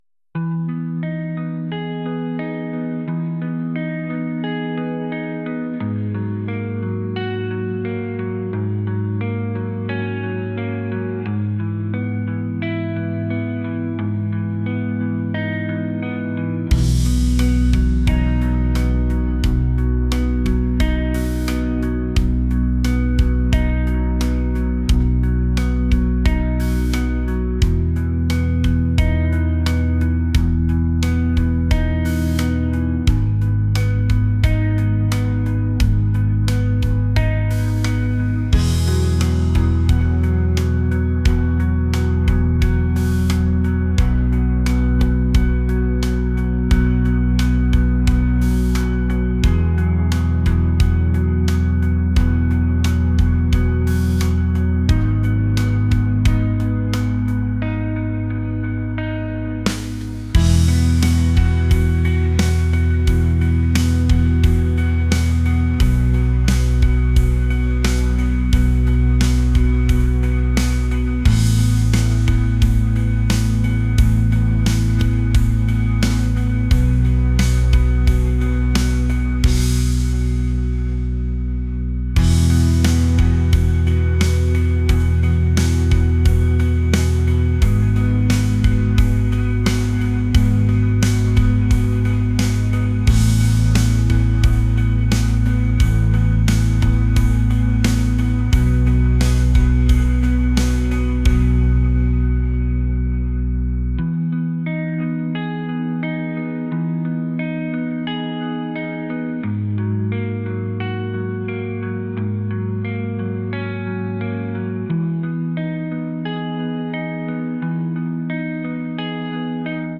indie | ambient | folk